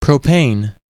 Ääntäminen
Ääntäminen US
IPA : /ˈproʊpeɪn/